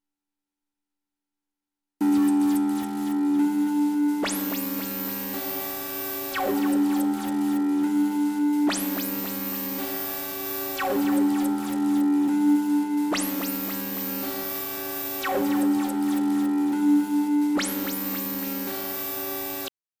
108 BPM
Dissonance — confuse enemies
Tritone/minor-second clashes, ring-mod lead, detuned murk bass